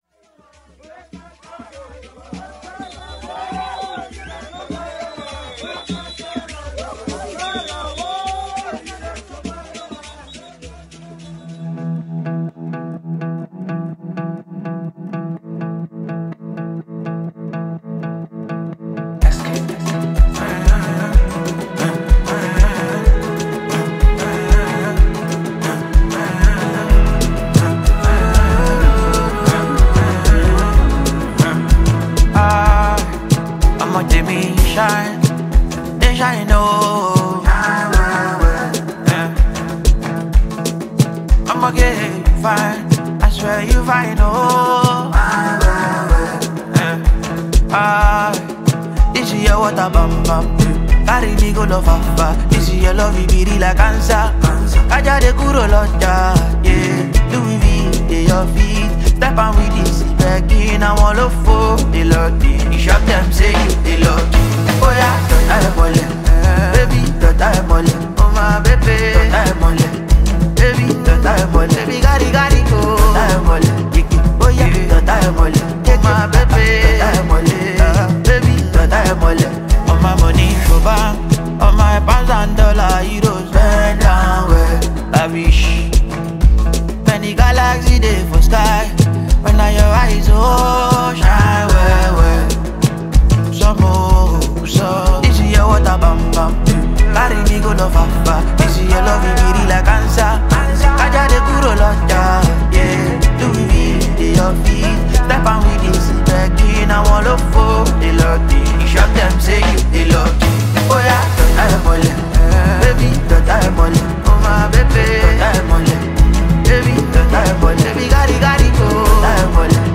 ” a love-inspired anthem that celebrates loyalty